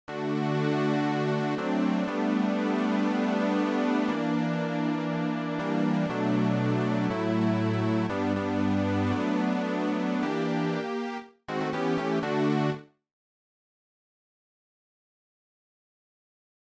Key written in: D Major
How many parts: 4
Type: Barbershop
All Parts mix: